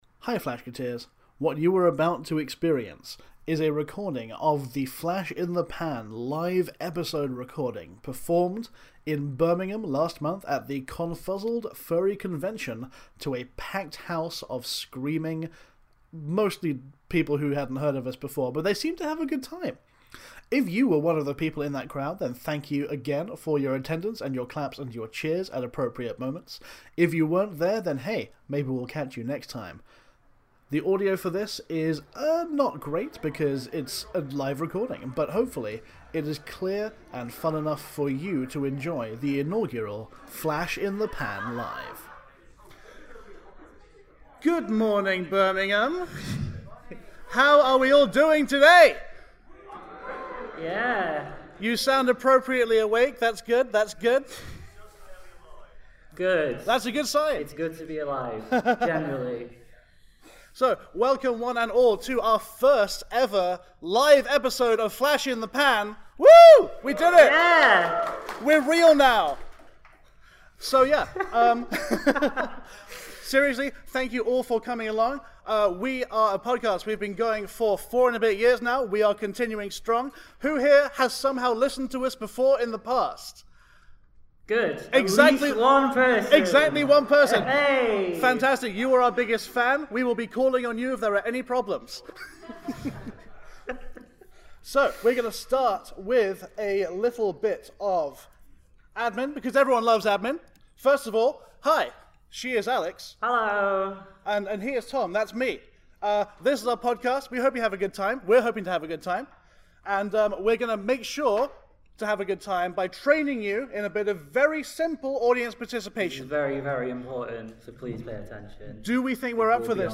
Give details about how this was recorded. Recorded at Confuzzled 2022 in Birmingham, England, and featuring a furry flash feast!